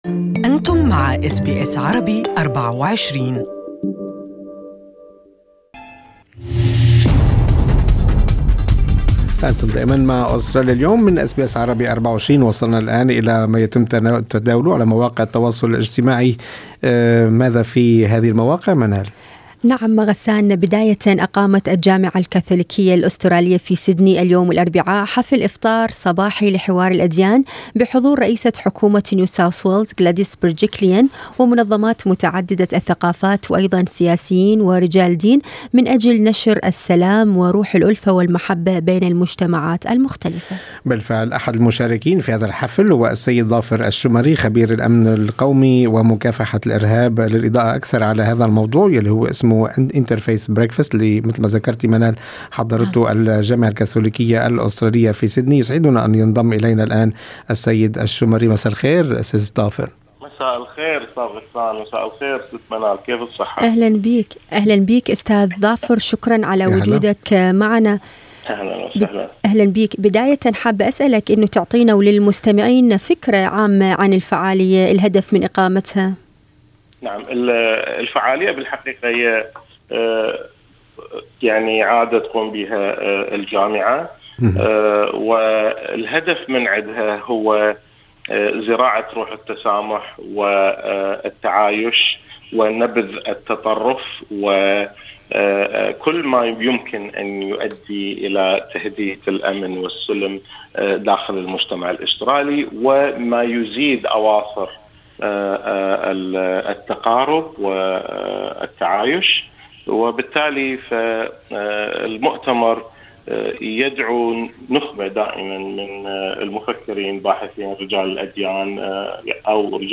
التدوين الصوتي